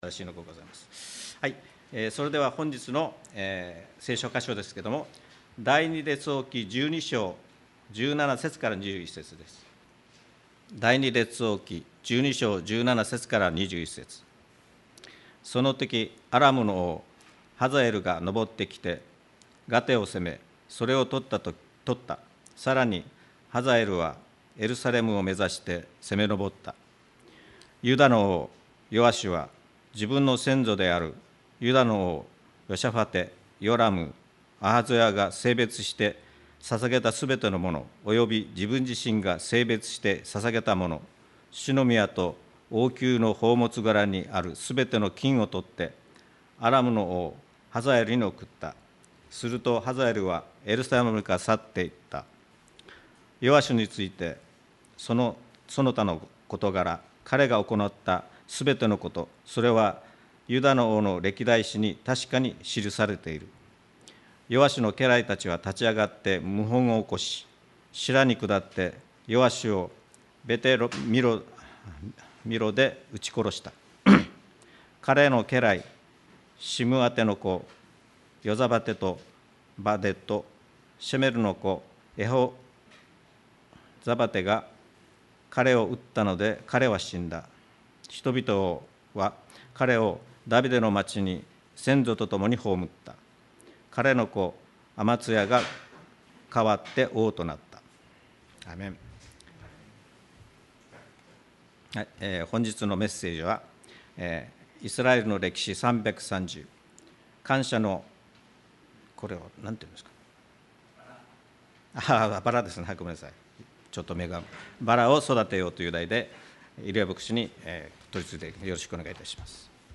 沖縄県浦添市にある沖縄バプテスト連盟所属ルア教会です。
2025年8月31日礼拝メッセージ